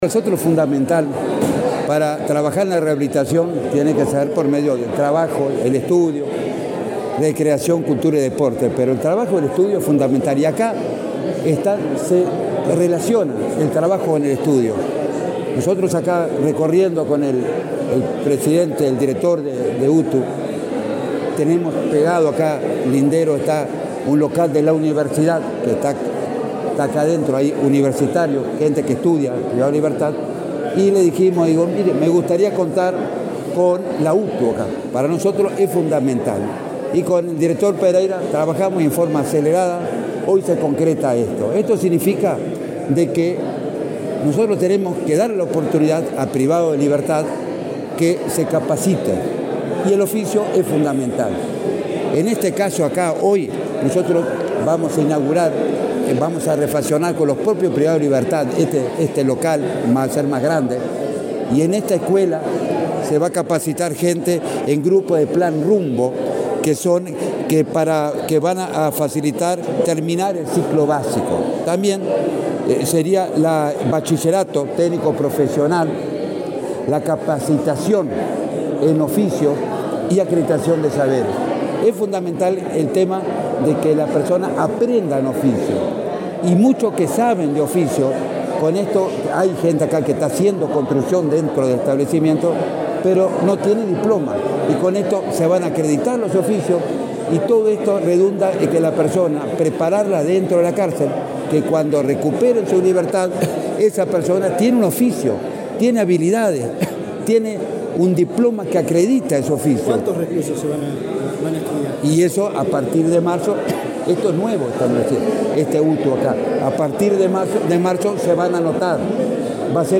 Declaraciones del director del INR, Luis Mendoza
El director del Instituto Nacional de Rehabilitación (INR), Luis Mendoza; el director general de la Universidad del Trabajo del Uruguay (UTU), Juan Pereyra: la presidenta de la Administración Nacional de Educación Pública (ANEP), Virginia Cáceres, y el ministro del Interior, Nicolás Martinelli, participaron, este martes 12 en la unidad n.° 4, Santiago Vázquez, en el acto de firma de un convenio que permitirá instalar la primera UTU en el sistema penitenciario. Luego Mendoza dialogó con la prensa.